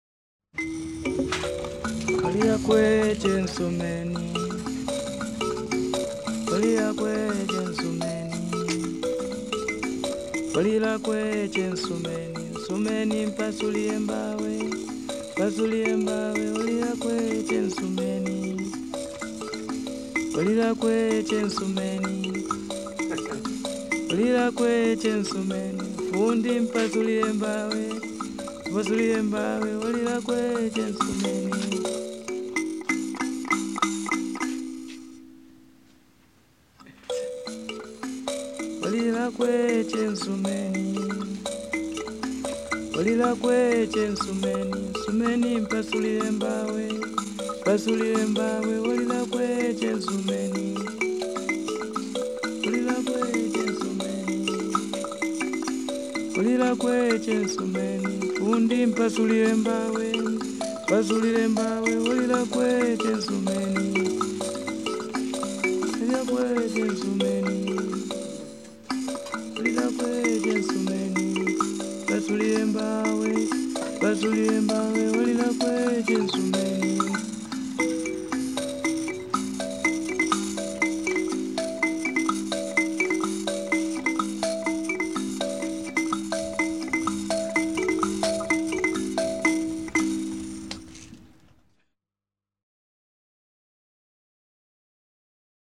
原始人声与传统器乐的创世纪之歌